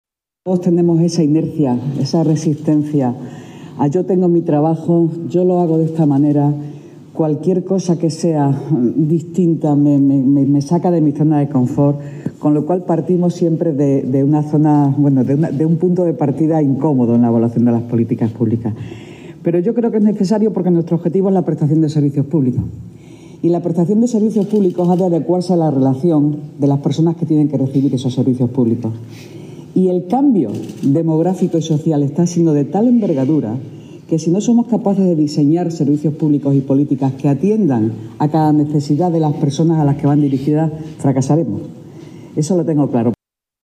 Nueva ventana:Declaraciones de la delegada de Economía y Hacienda, Engracia Hidalgo